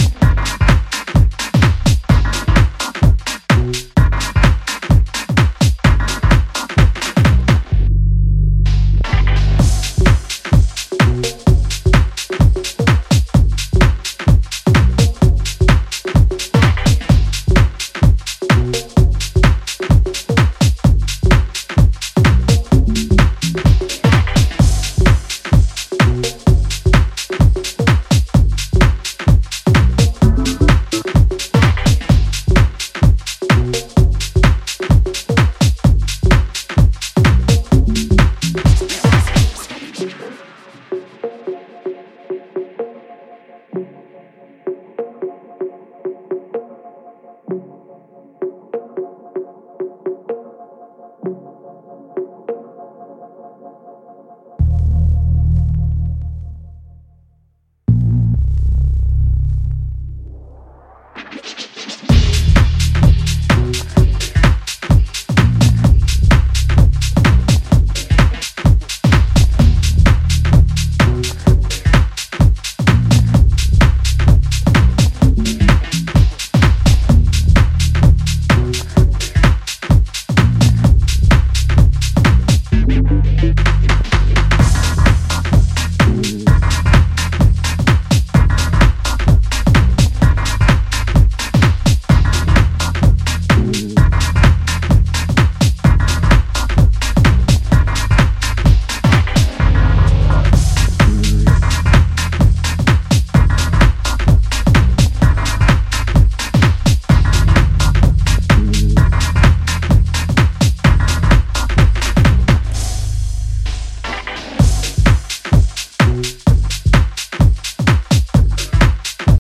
本作では、ヘヴィなボトムと分厚いベースラインで爆走する、ダークでパワフルなミニマル・テック・ハウスを展開。